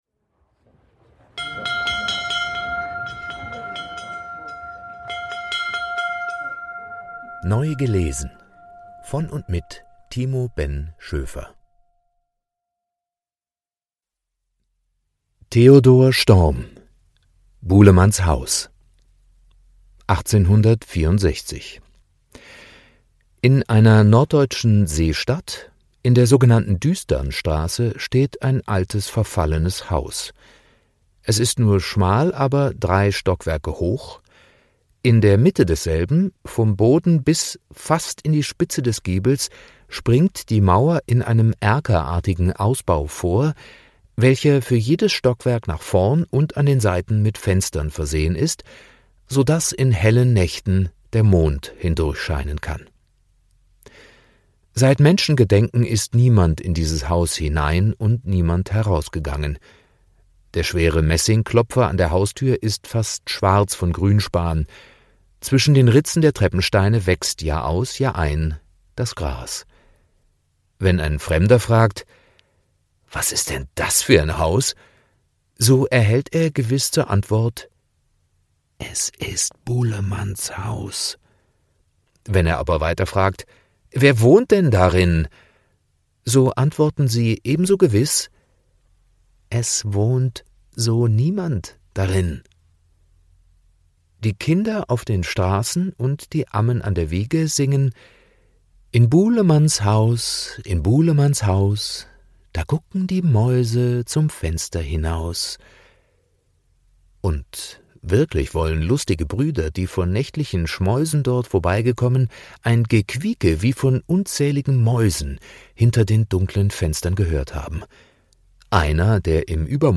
Literatur des 19.Jahrhunderts,vorgelesen